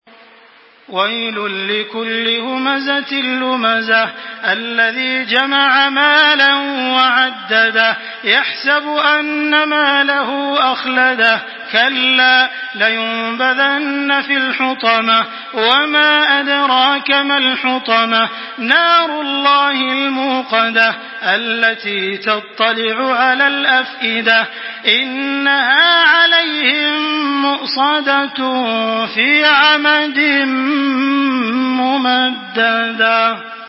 Surah Al-Humazah MP3 in the Voice of Makkah Taraweeh 1425 in Hafs Narration
Murattal